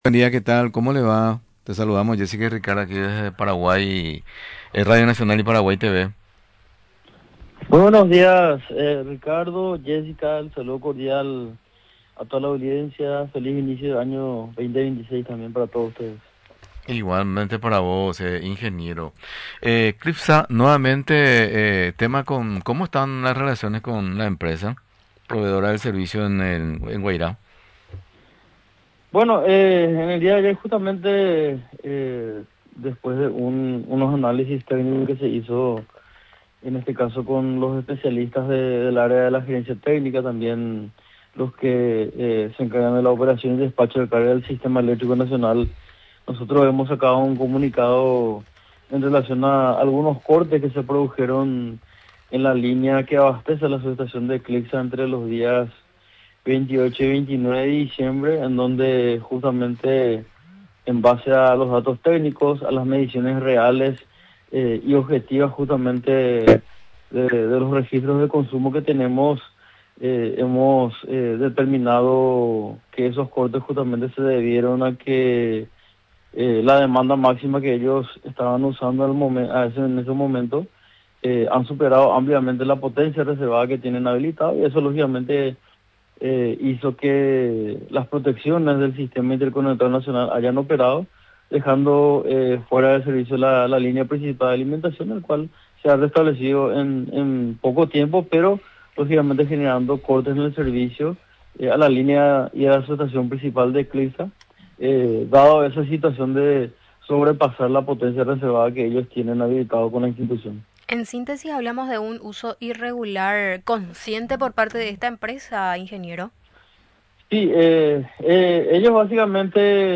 El entrevistado señaló que la empresa estaría violando las normativas actuales al no declarar estas actividades, las cuales requieren condiciones de abastecimiento y facturación mucho más elevadas.